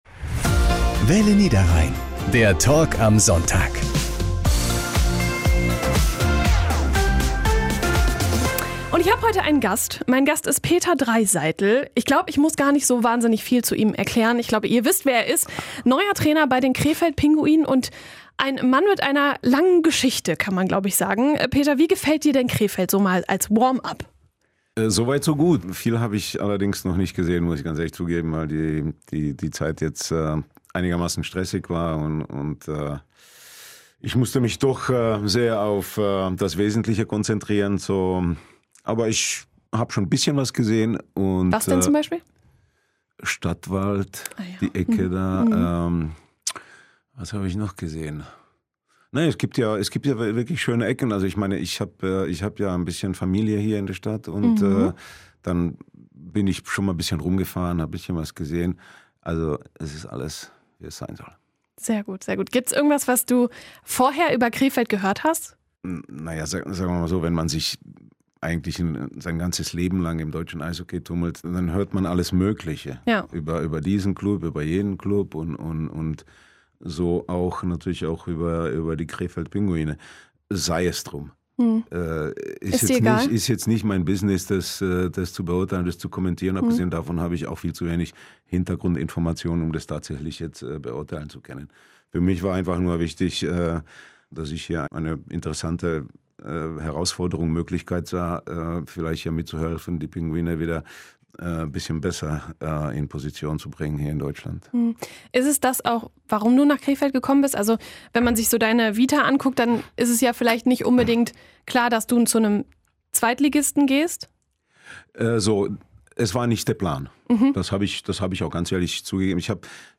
Peter Draisaitl hat sein Leben im deutschen Eishockey verbracht und arbeitet jetzt mit seiner Erfahrung bei den Krefeld Pinguinen. In unserem Talk am Sonntag haben wir mit ihm über seine neue Aufgabe gesprochen.